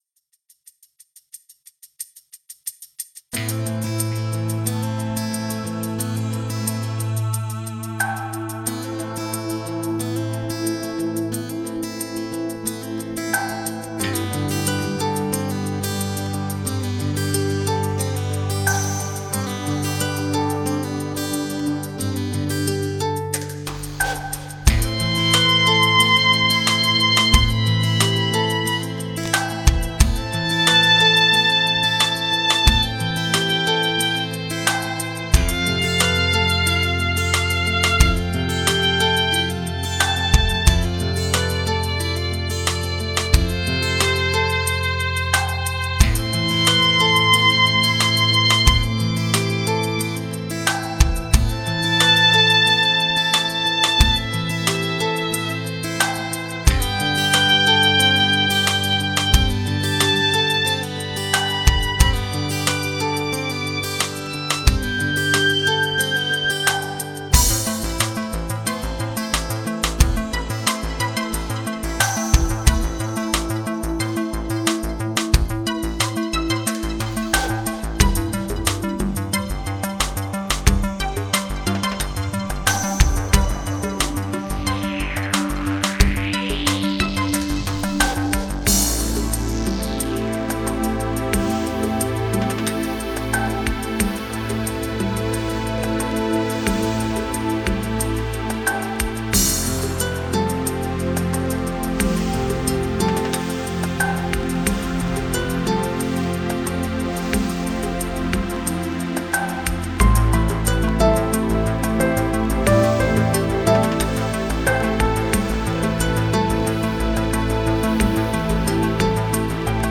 Ich mache hauptsächlich elektronische, instrumentale Musik.